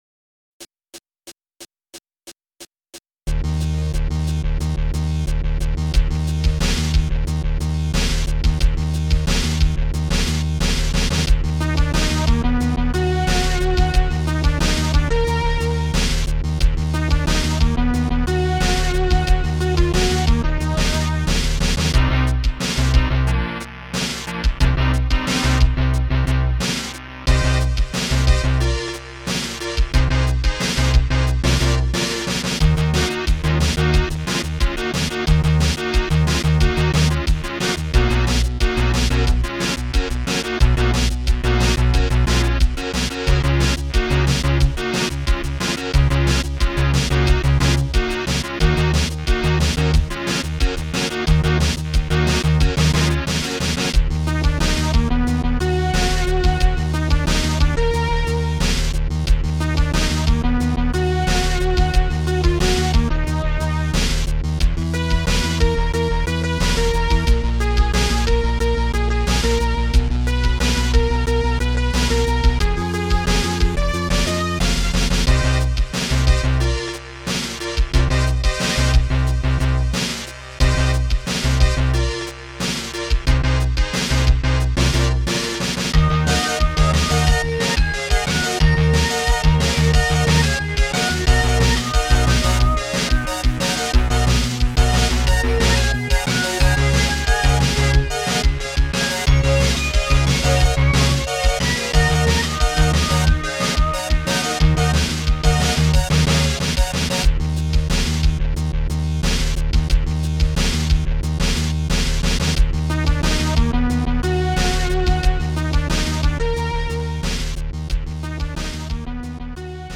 Instrumental Electronic